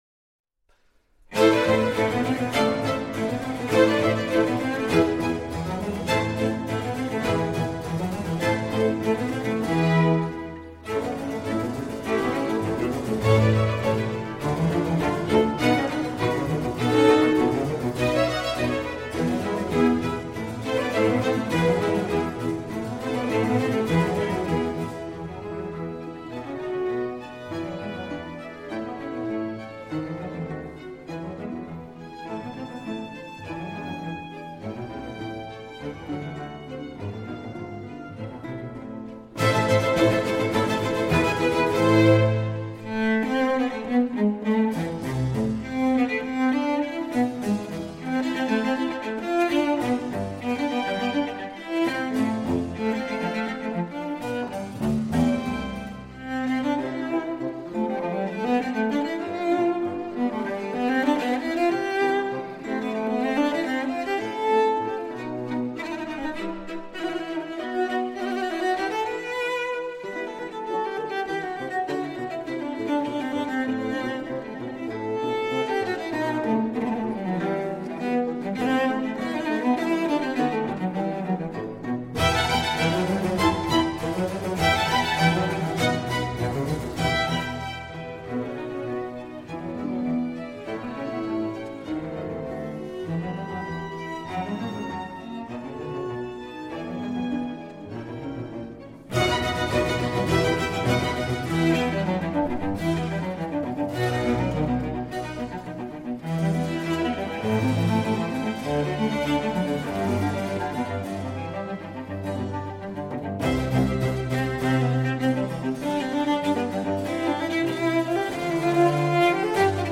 01 - Cello Concerto in A Minor, RV 419_ I. Allegro.mp3